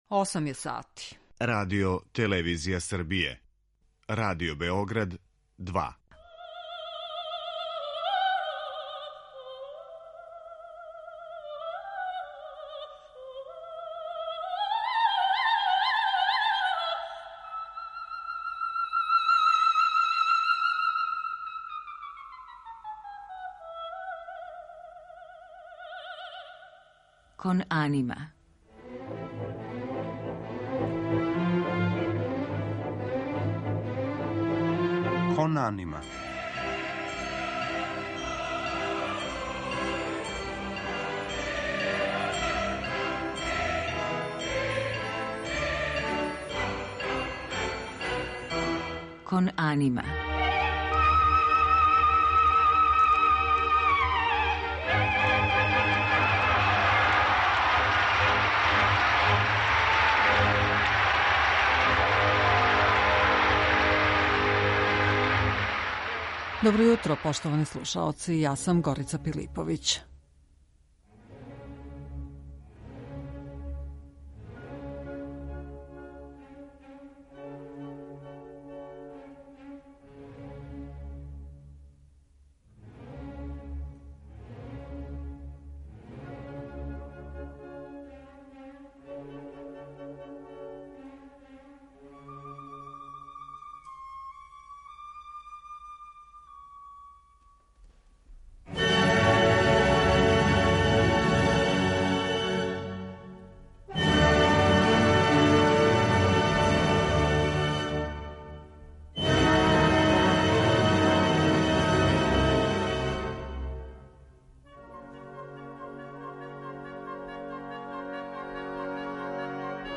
Оперски хорови
Биће то фрагменти из ремек-дела највећих оперских композитора - Вердија, Вагнера, Моцарта, Римског-Корсакова...